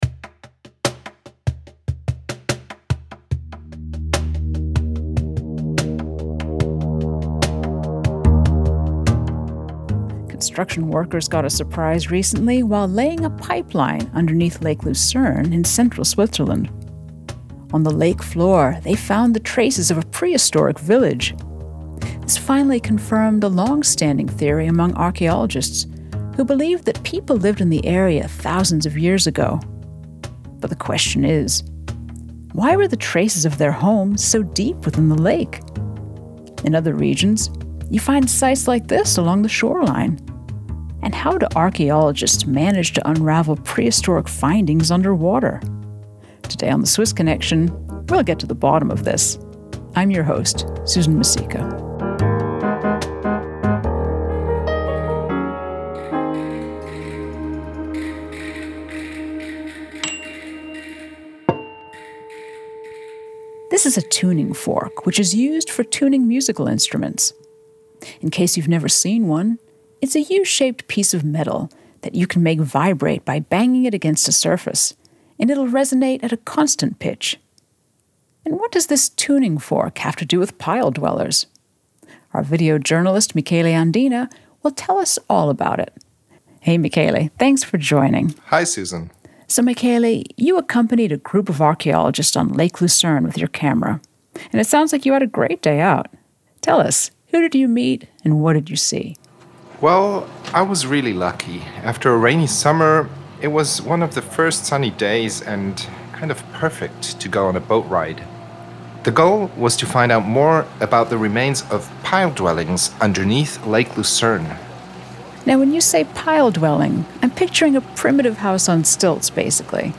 People discuss the technology used to find prehistoric ruins under Lake Lucerne.
On this episode of The Swiss Connection podcast, we take a boat ride to get to the bottom of this mystery. While onboard, we meet a team of international experts and learn about an unusual technique using sound to map the depths of a lake.